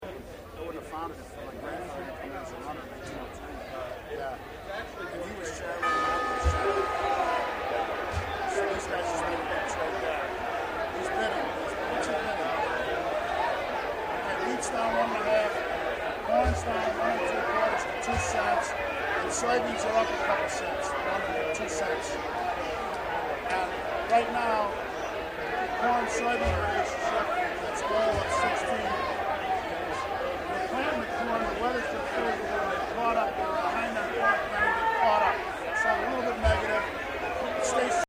cbot-07-bell.mp3